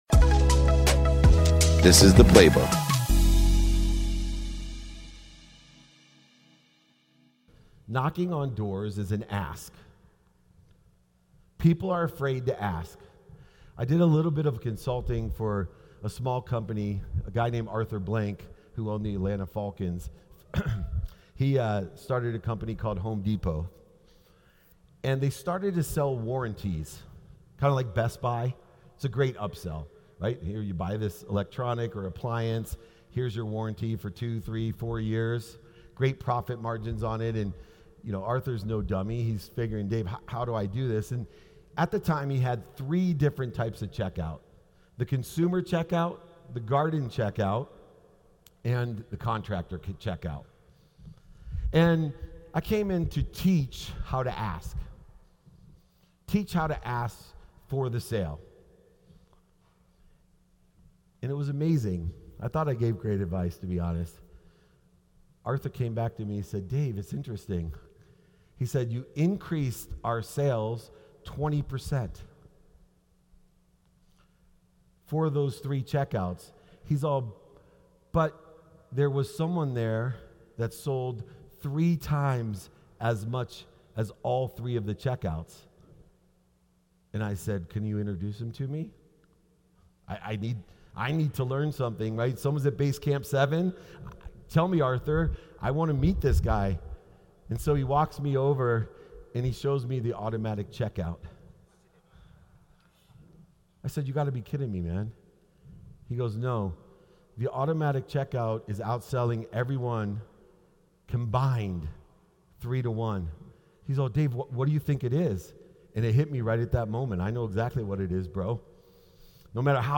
I had the honor of keynoting